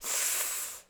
SFX_Battle_Vesna_Defense_14.wav